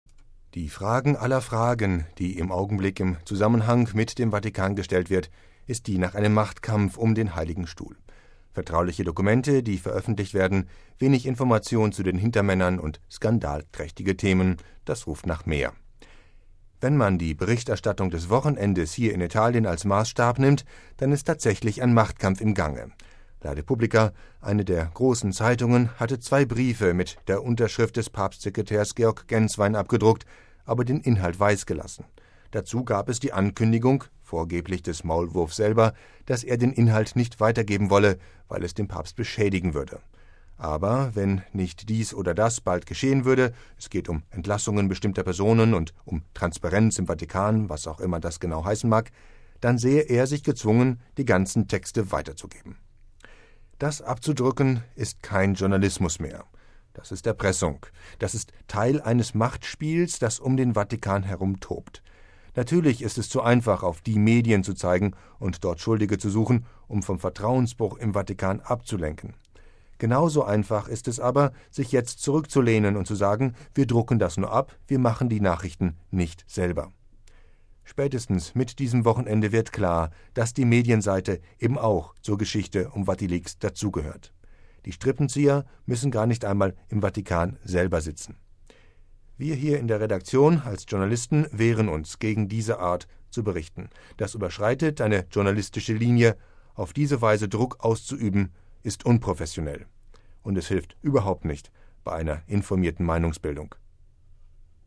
Vatileaks: Ein Kommentar